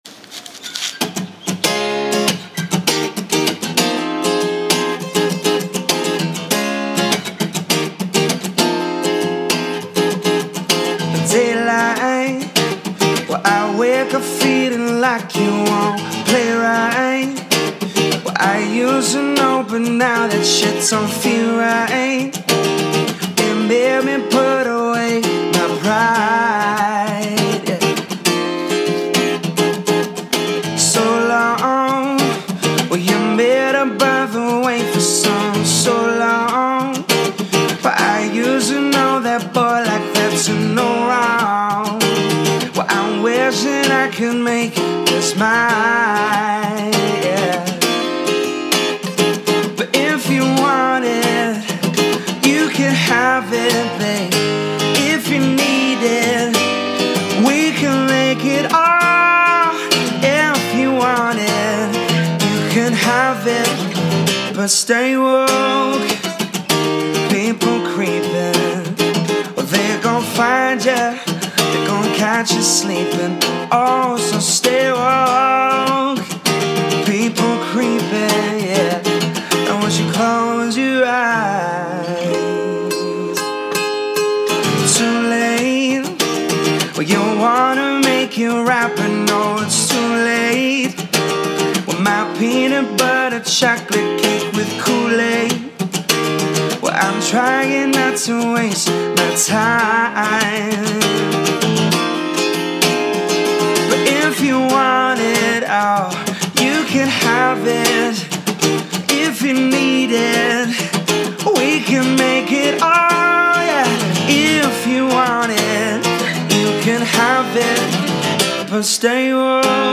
Vocals | Guitar | Looping